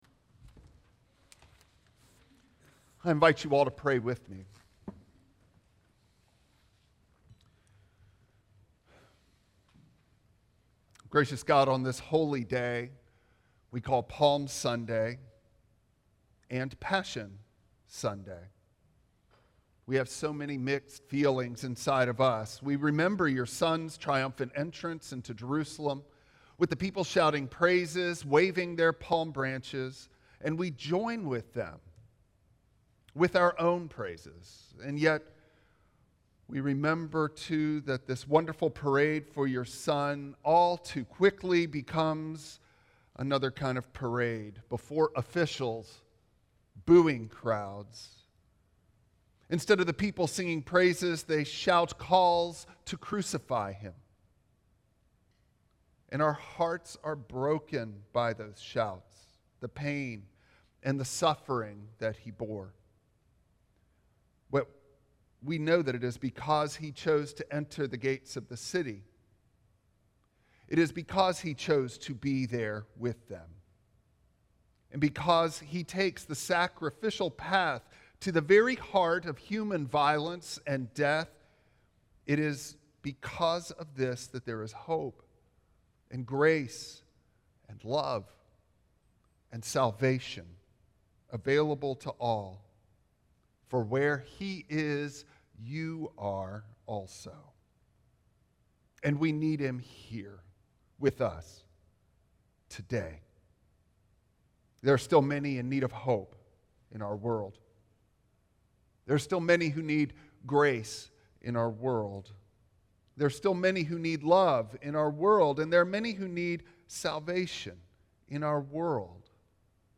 Passage: Matthew 21:1-13 Service Type: Traditional Service Bible Text